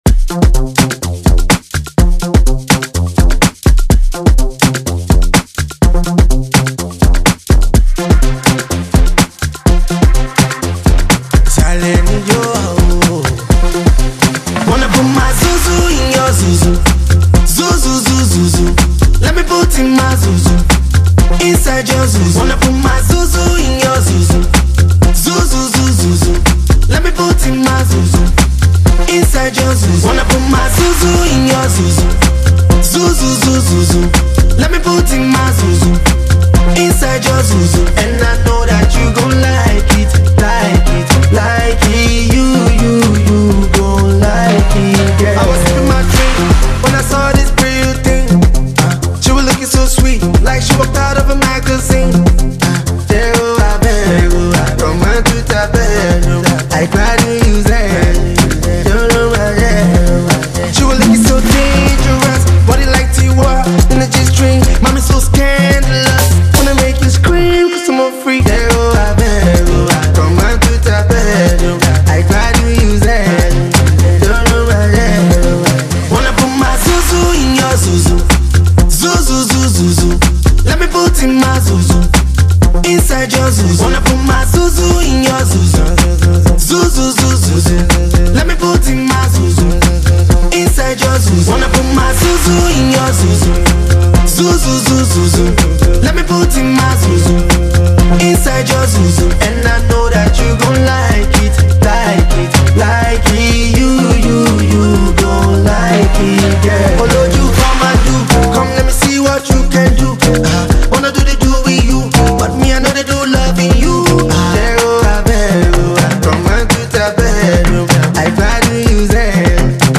Nigerian singer and songwriter